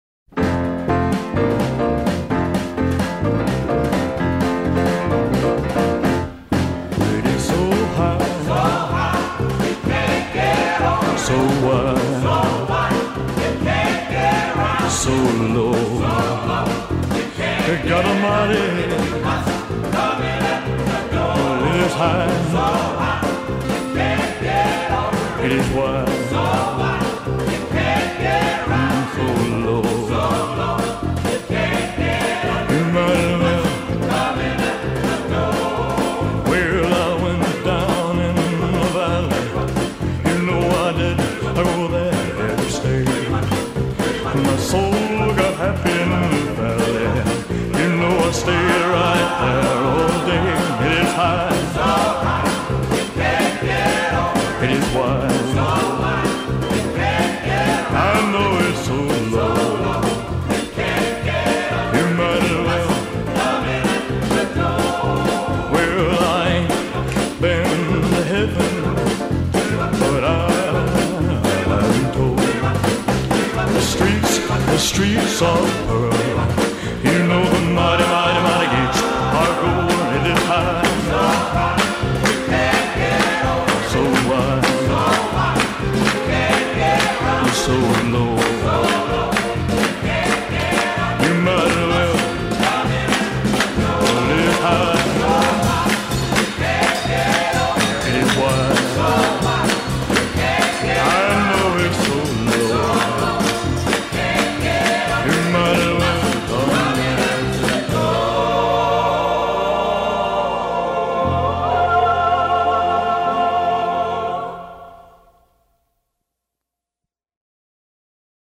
音乐类型：西洋音乐